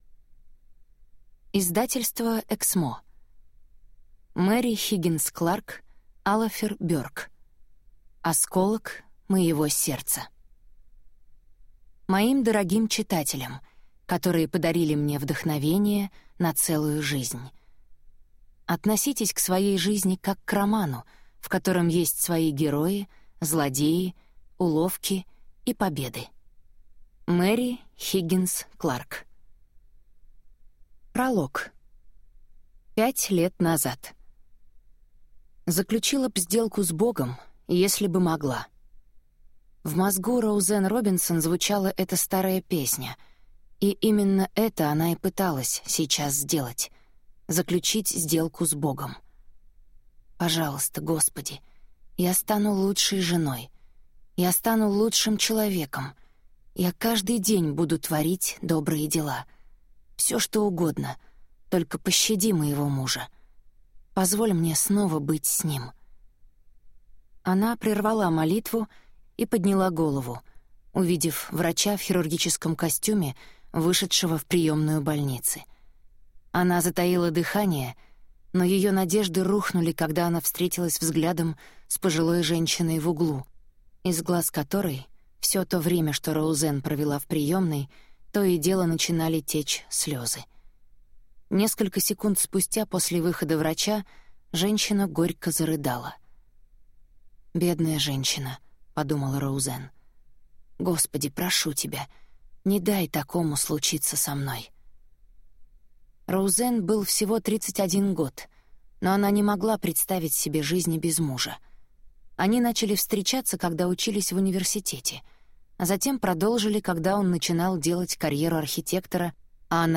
Аудиокнига Осколок моего сердца | Библиотека аудиокниг
Прослушать и бесплатно скачать фрагмент аудиокниги